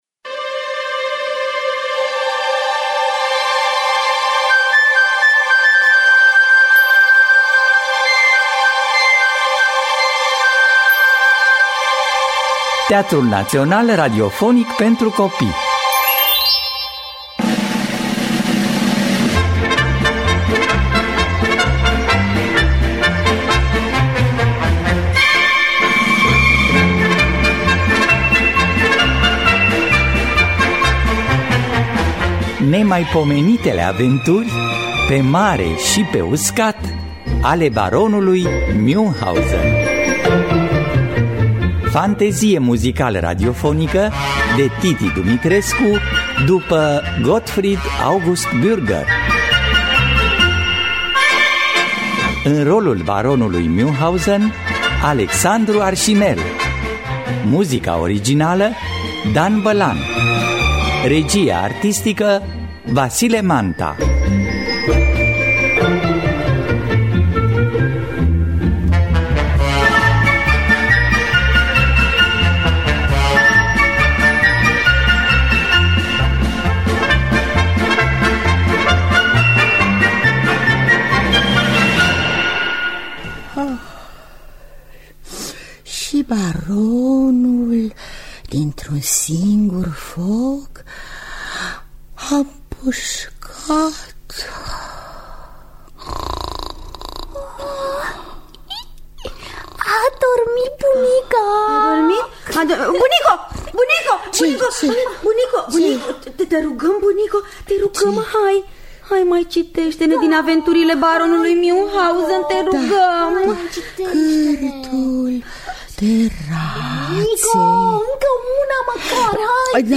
Aventurile baronului Münchhausen de Gottfried August Bürger – Teatru Radiofonic Online